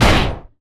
IMPACT_Generic_14_mono.wav